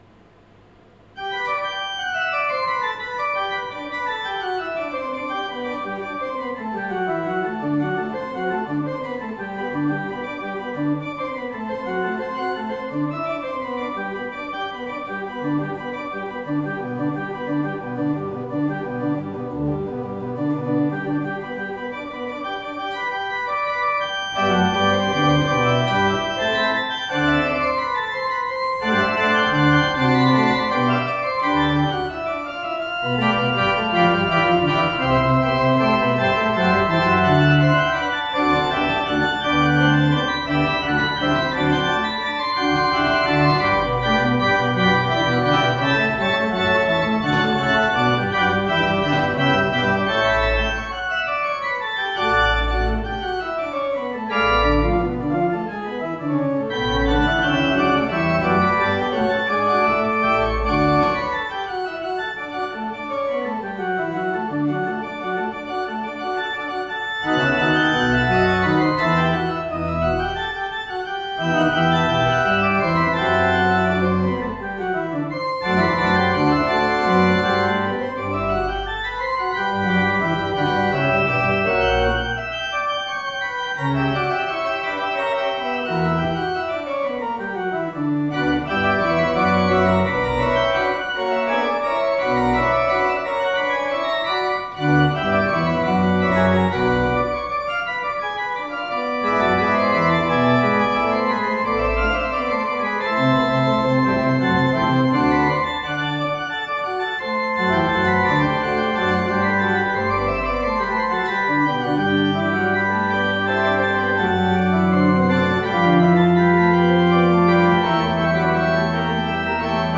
The casework and stop knobs are based on an 18th century English style as is the general sound of the instrument.